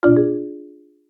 CriticalAlert.ogg